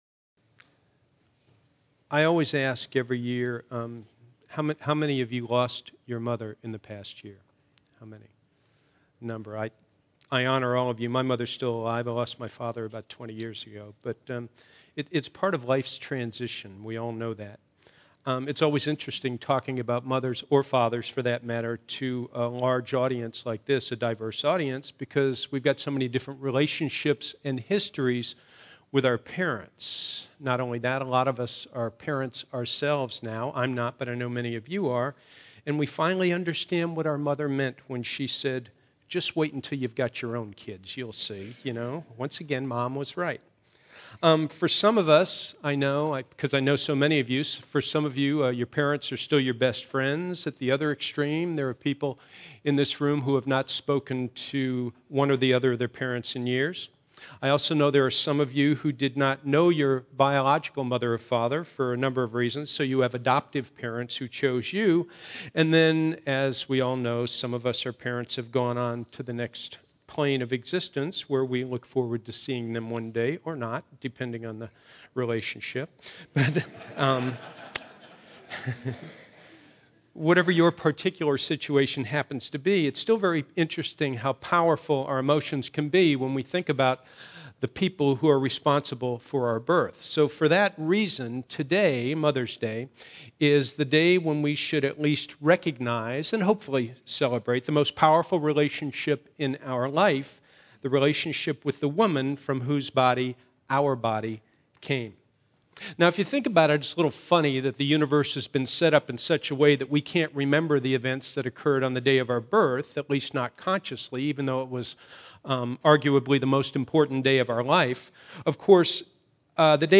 Our one-hour Sunday services are open and comfortable, with music, laughter, and interesting talks.